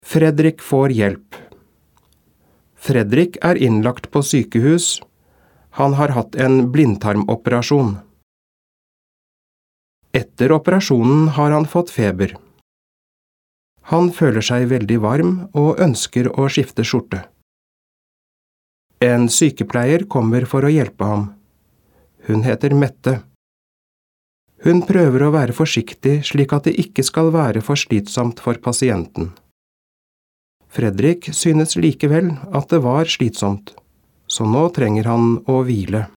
Diktat leksjon 2
• Tredje gang leses teksten sammenhengende, og du skal kontrollere det du har skrevet.